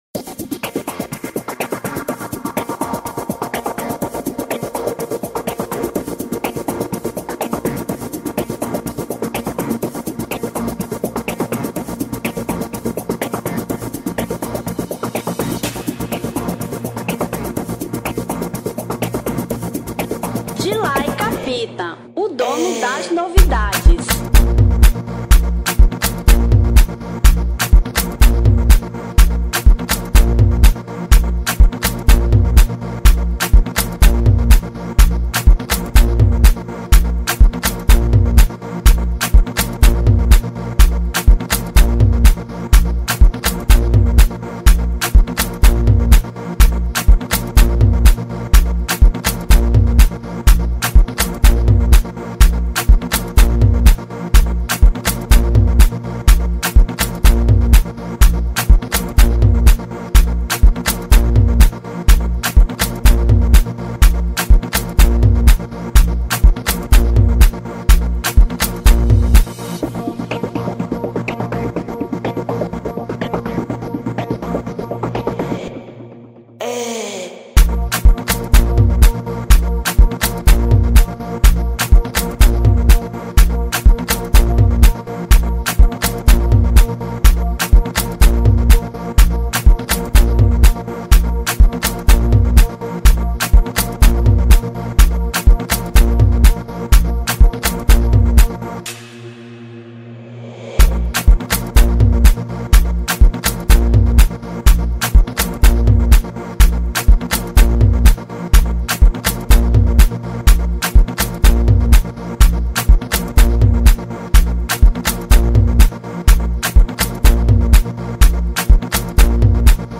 Instrumental 2025